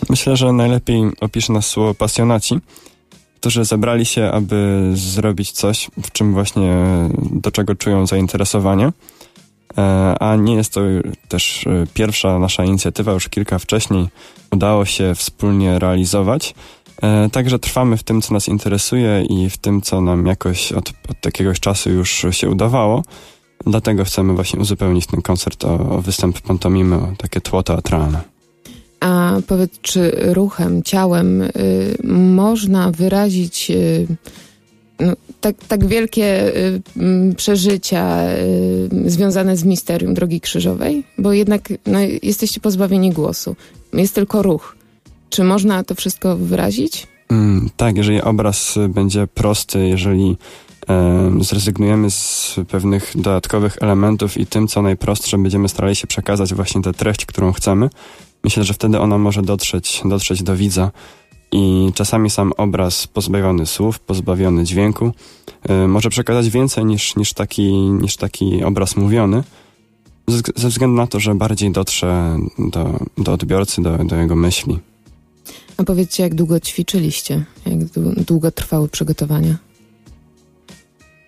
Blisko 100 osób obejrzało oratorium słowno-muzyczne „Ślad losu”. Misterium drogi krzyżowej przedstawiły, 4 kwietnia w Auli PWr, grupa muzyczna i grupa teatralna z duszpasterstwa akademickiego „Redemptor”.
Śpiewy i grę na instrumentach wzbogaciła pantomima.